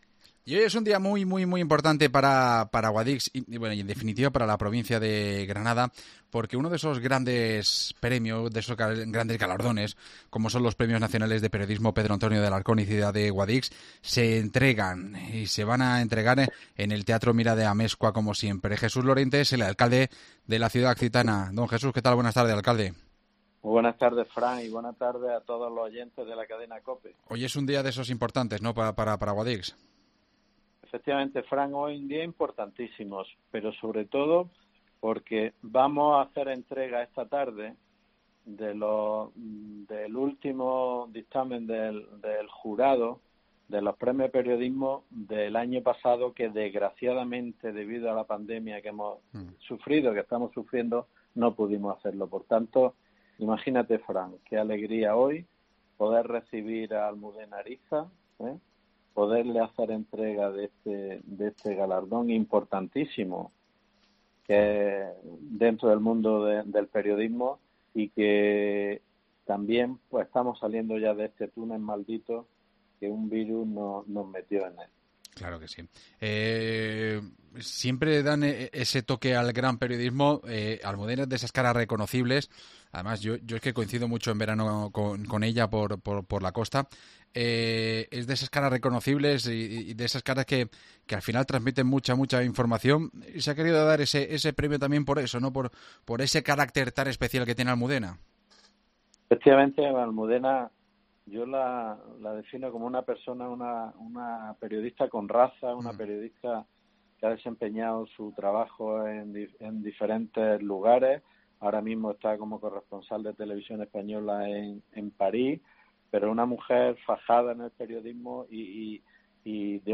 AUDIO: Hablamos con su alcalde, Jesús Lorente, sobre la importancia del evento